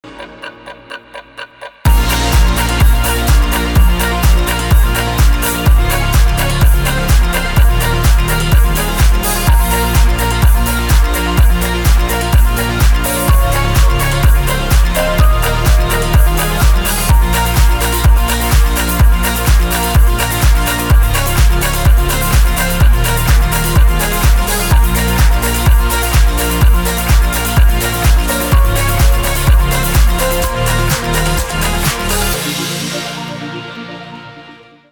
OST
электроника
саундтрек